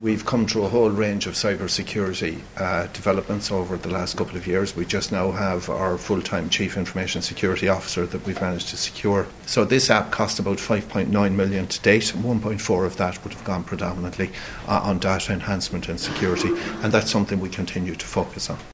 The HSE’s chief executive, Bernard Gloster, says significant work has gone into ensuring medical data will be protected……………